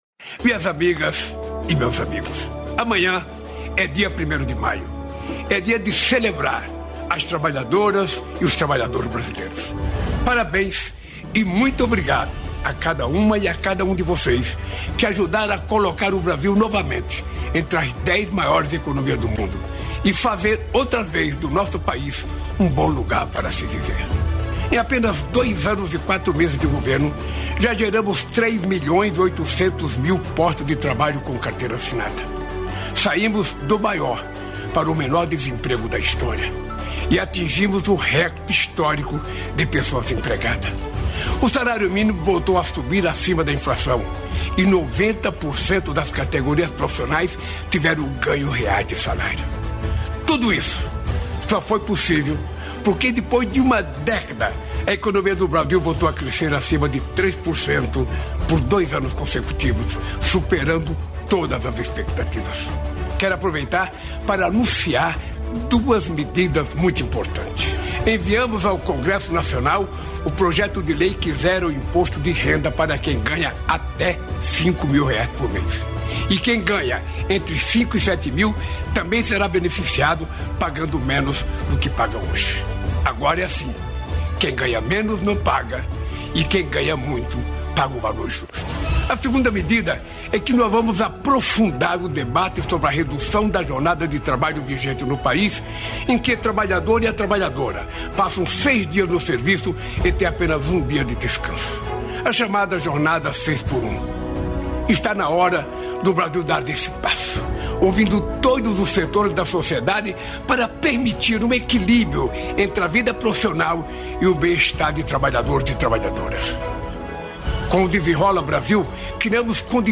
Ouça a íntegra do pronunciamento do presidente da República Luiz Inácio Lula da Silva em rede nacional de rádio e TV, nesta quarta-feira (30), em virtude do Dia do Trabalhador, celebrado em 1° de maio.